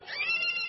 rabbit_injured.mp3